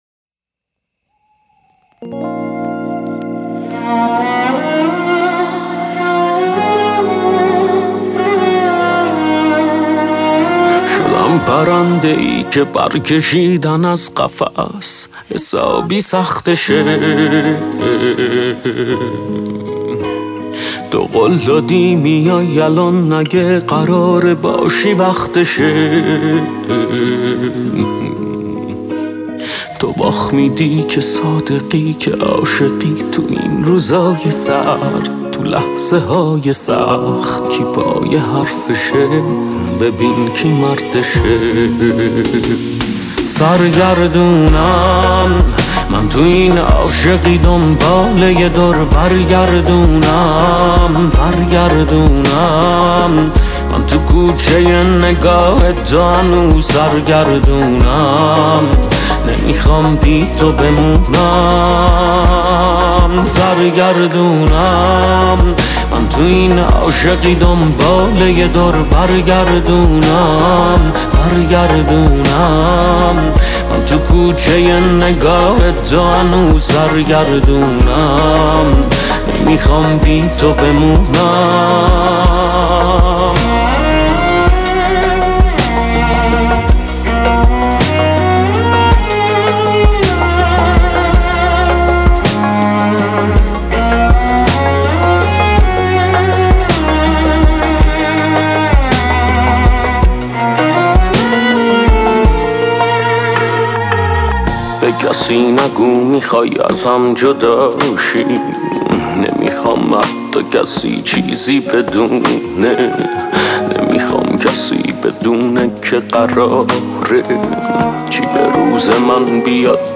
یه آهنگ چس ناله ای دیگه